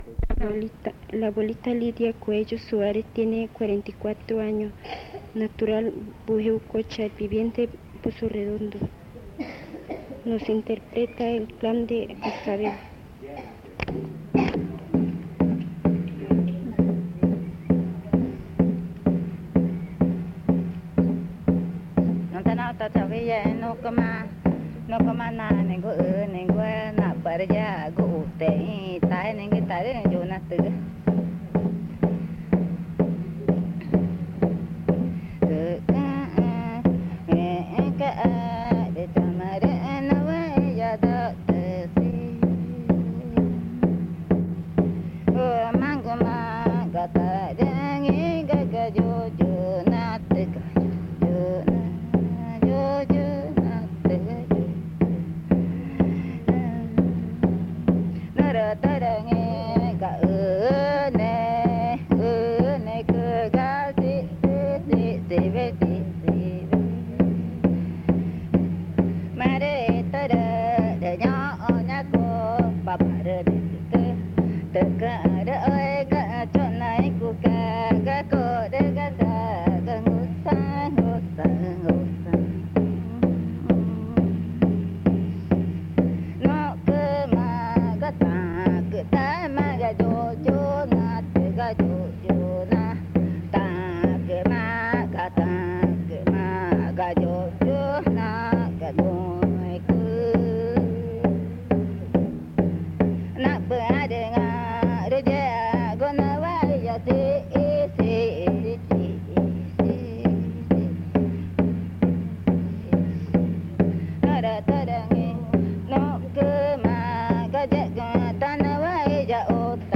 Canto del clan Cascabel
Pozo Redondo, Amazonas (Colombia)
La abuela usa el tambor meintras canta.
The elder uses the drum while she sings.